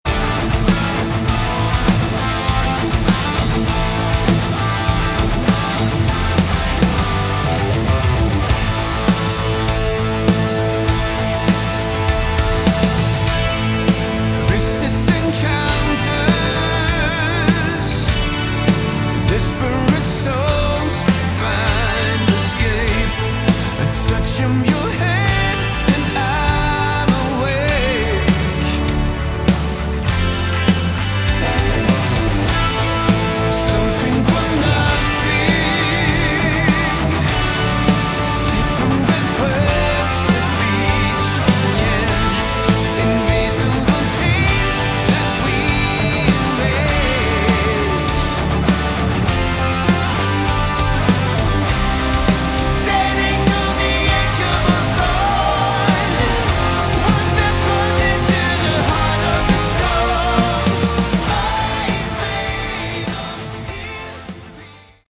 Category: Hard Rock
lead vocals
bass
guitars
drums